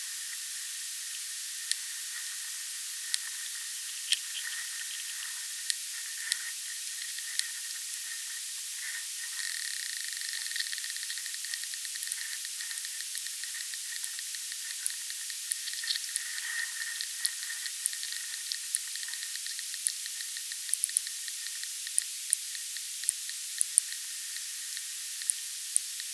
Species: Acilius sulcatus Recording Location: Europe: United Kingdom: England: Norfolk: Bodham: Pond (SABA)
hydrophone (standard)
Freshwater Acoustics